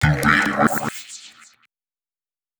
034 male.wav